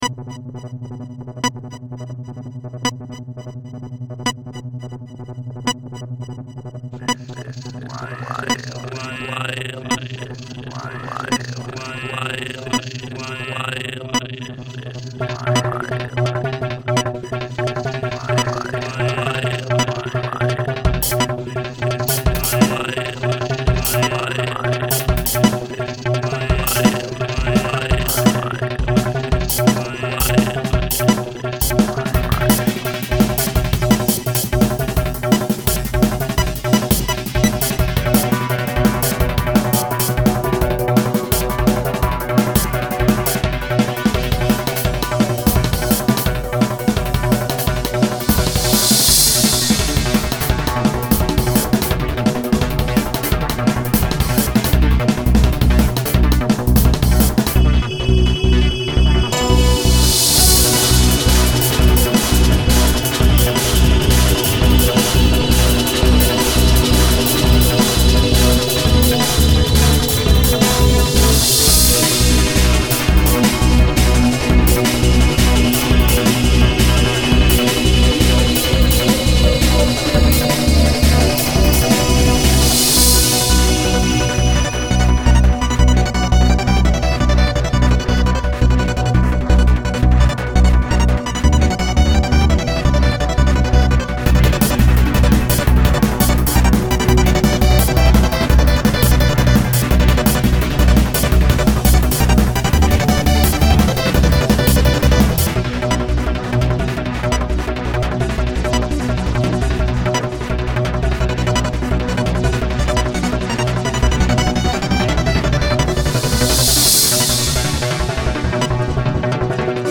dance/electronic
Drum & bass
IDM
Trip-hop